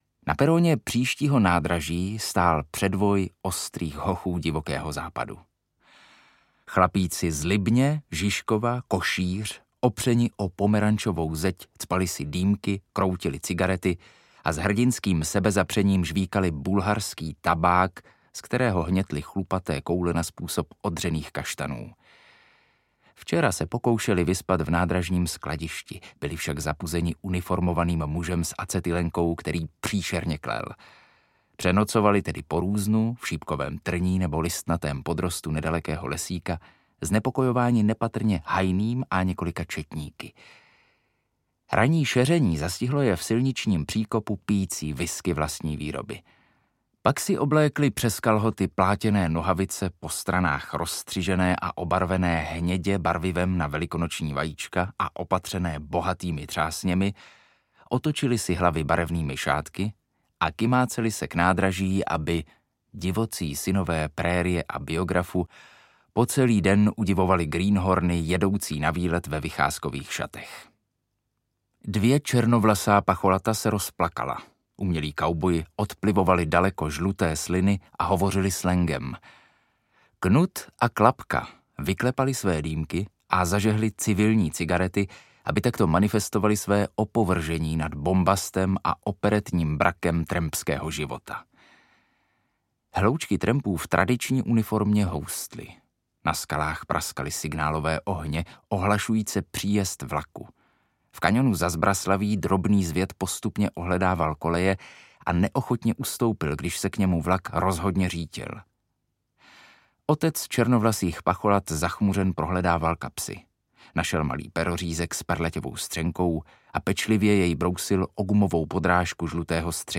Arizona audiokniha
Ukázka z knihy